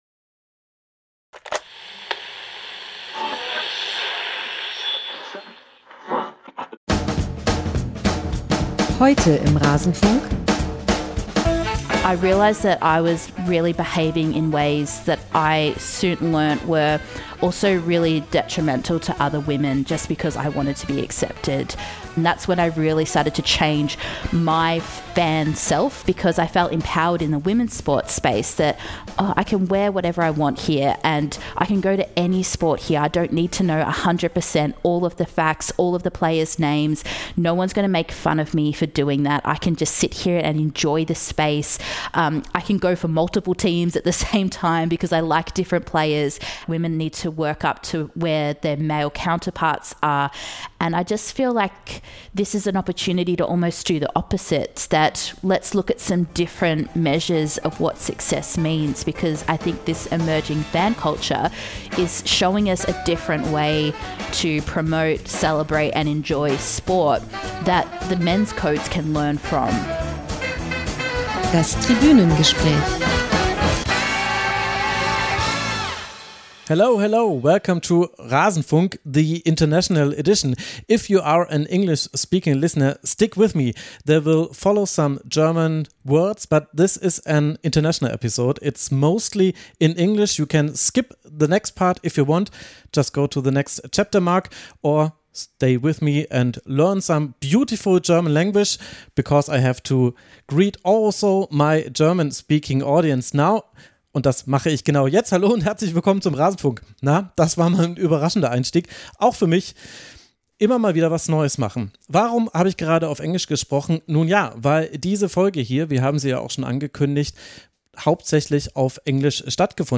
Transkript: Das Interview auf Deutsch